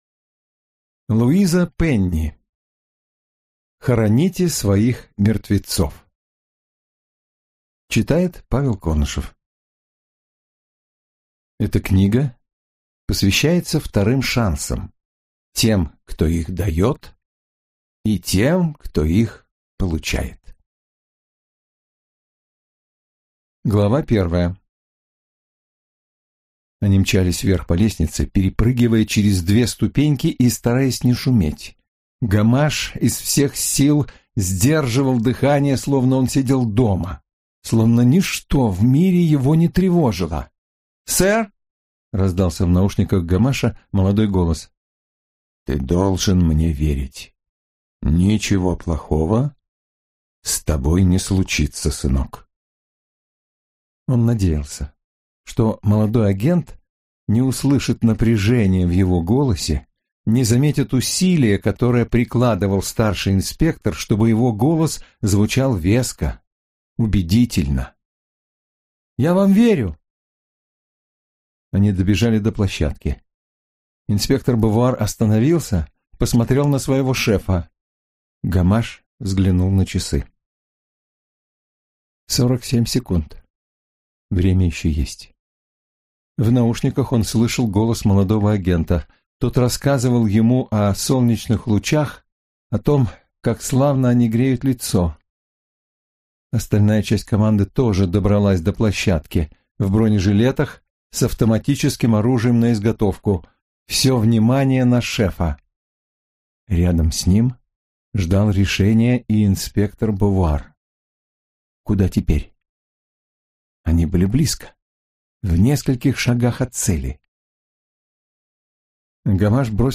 Аудиокнига Хороните своих мертвецов - купить, скачать и слушать онлайн | КнигоПоиск